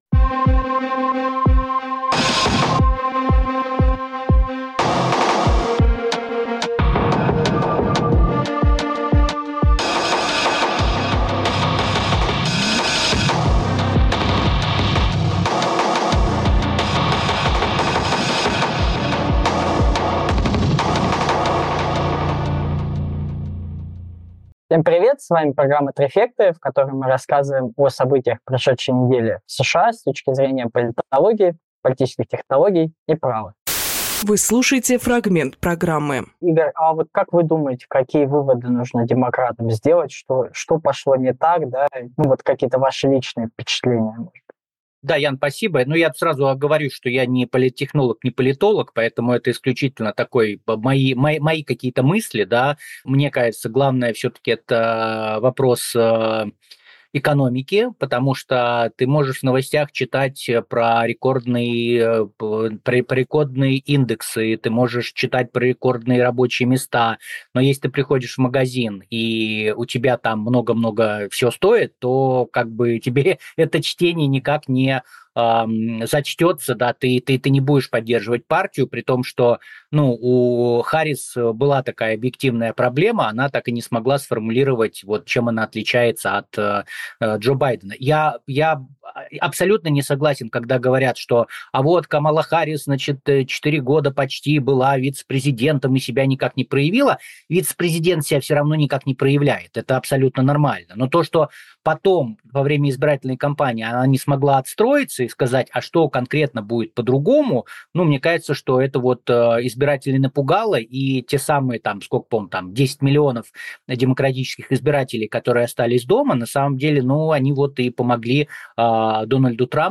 Фрагмент эфира от 13 ноября